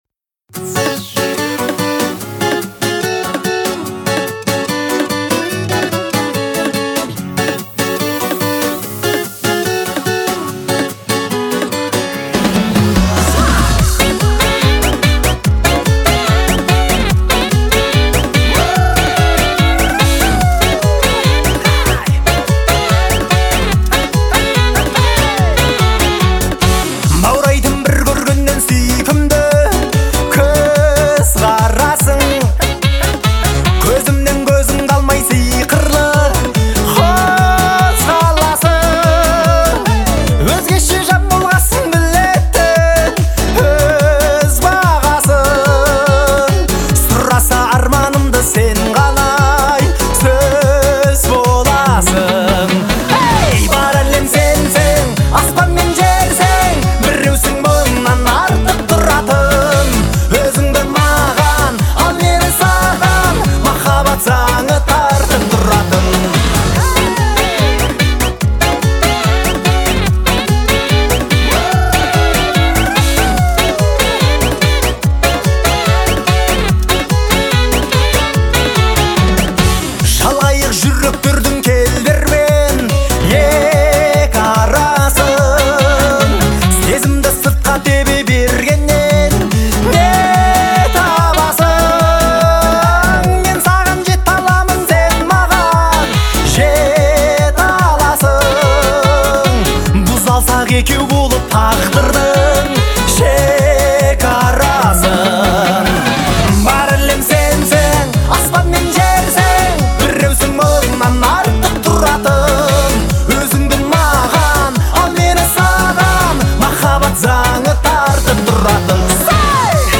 это яркий образец казахской поп-музыки.